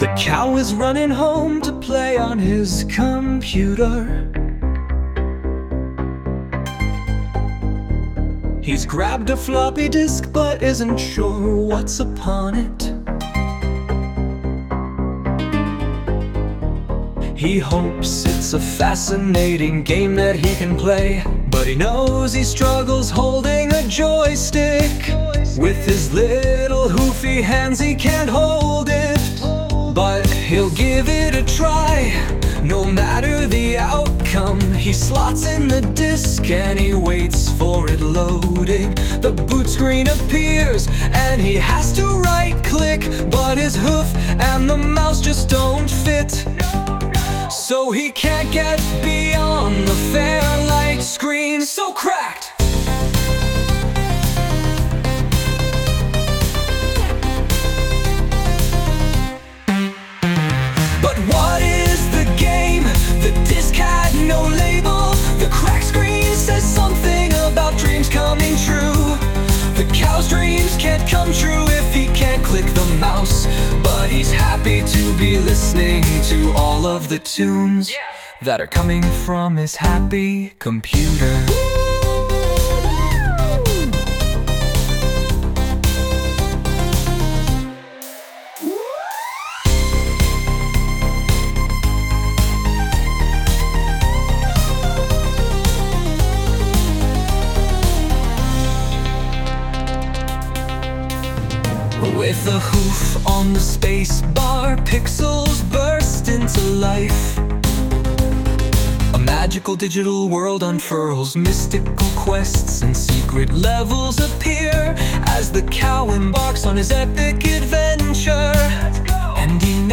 Lyrics : By me
Sung by Suno